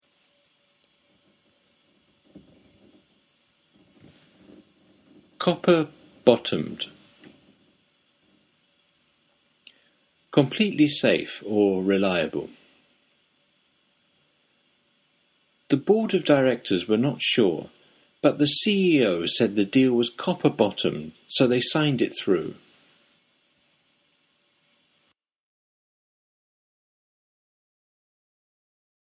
ネイティブによる発音は下記のリンクをクリックしてください。
Copperbottomed.mp3